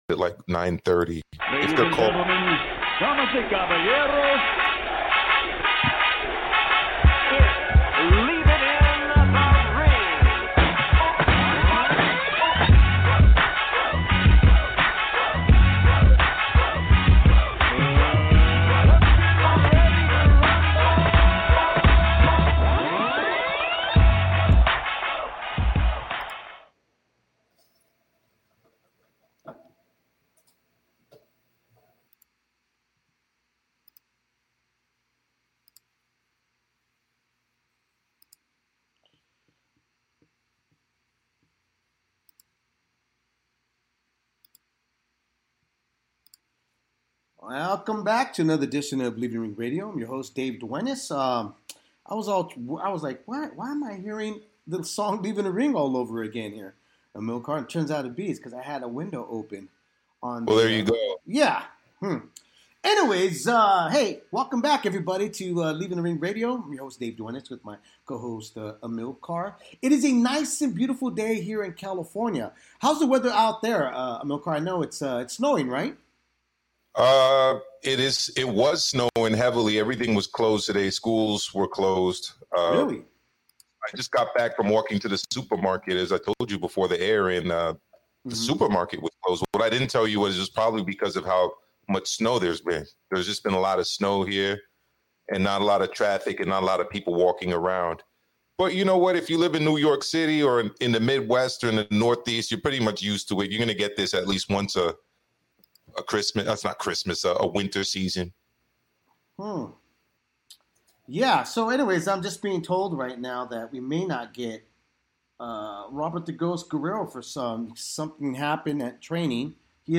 fan interactive debates, breaking news, and in-depth interviews with the top names in the sport